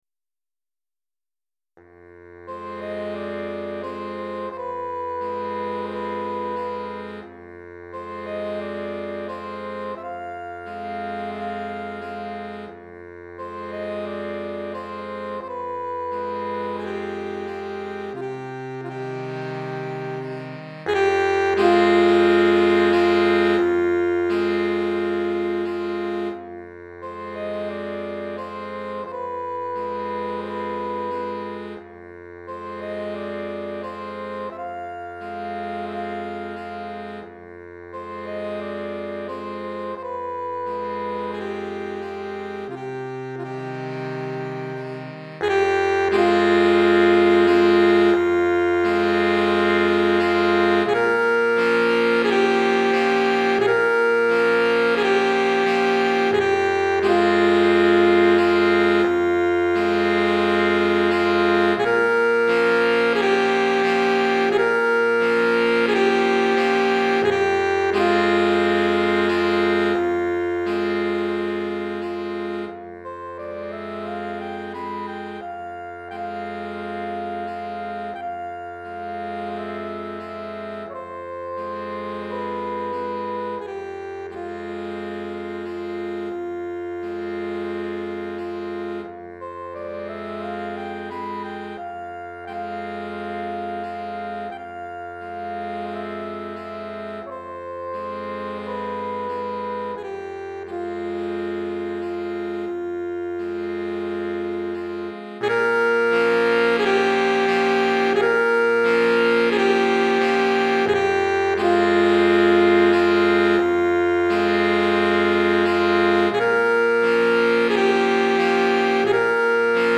Saxophone Soprano, 2 Saxophones Alto et Saxophone